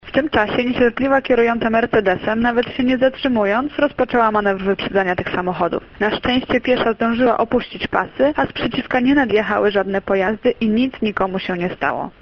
Relacjonuje